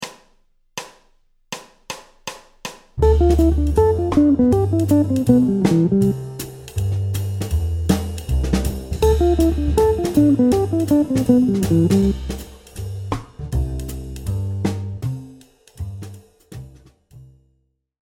Phrase 12 – Cadence ii V7 I en Majeur
Chaque mesure démarre par un ‘motif 5 3 2 1’